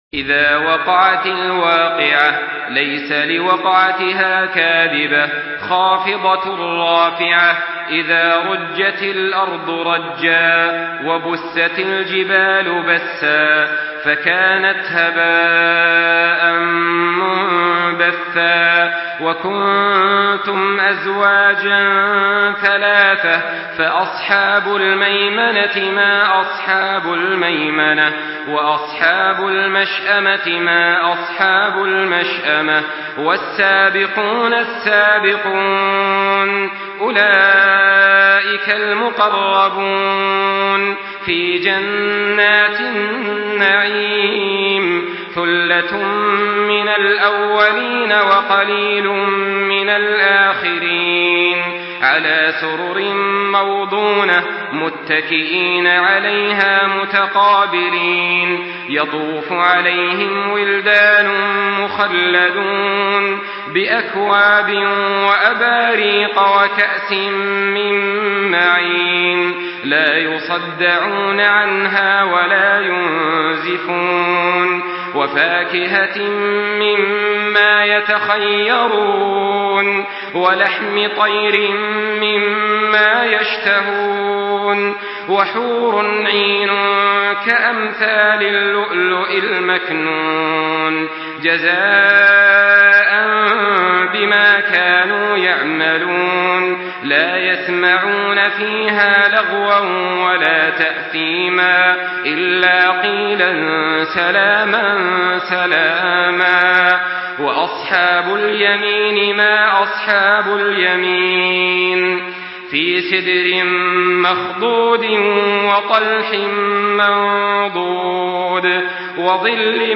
Surah Vakia MP3 by Makkah Taraweeh 1424 in Hafs An Asim narration.
Murattal